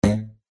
Звуки батута
На этой странице собраны звуки батута: прыжки, отскоки, скрип пружин и другие эффекты.